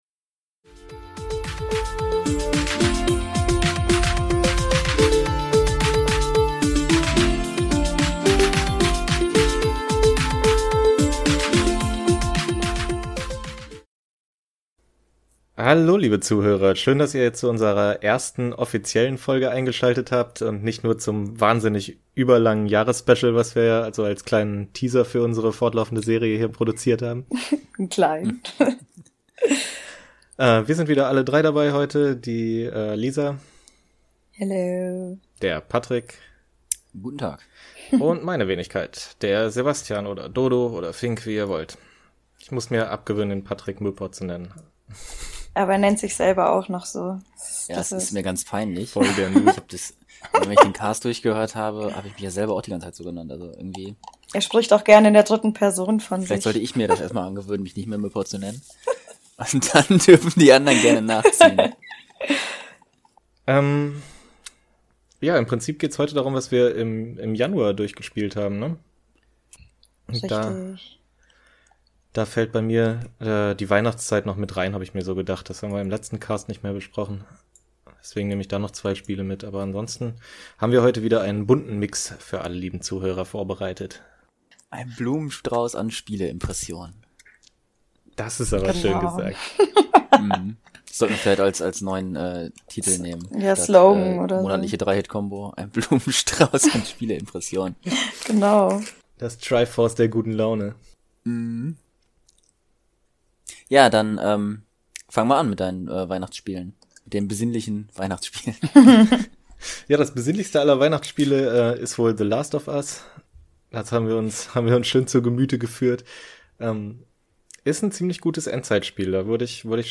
Die drei Vielspieler haben das Tutorial hinter sich gelassen und kräftig auf Start gepresst! Mit Level 1-1 beginnt das neue „durchgespielt“ Format endlich offiziell und bietet uns ab jetzt regelmäßig die Gelegenheit dazu über alle Games zu quatschen, die wir im vergangenen Monat durchgespielt haben.